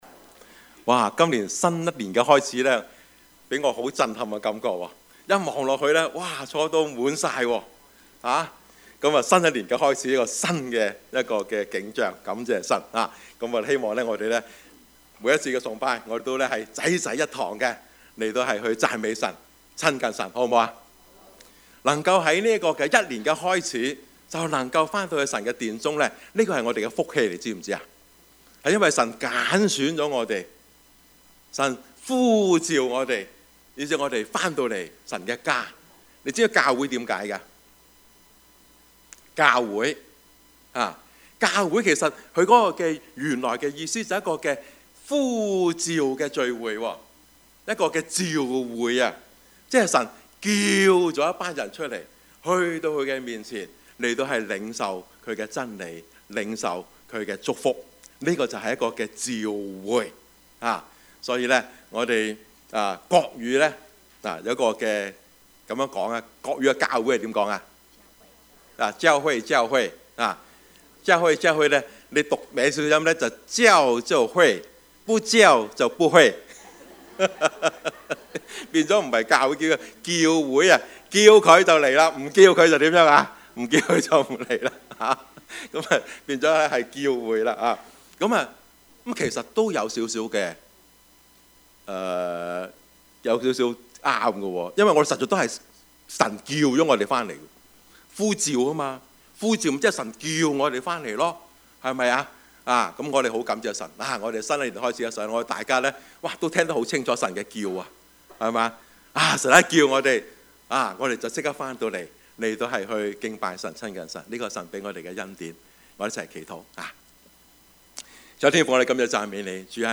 Service Type: 主日崇拜
Topics: 主日證道 « 幸福入門 2 讀經有什麼味道?